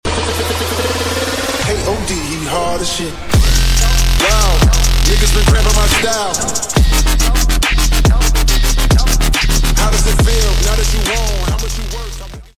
BMS Armor Burnout – Smoke Sound Effects Free Download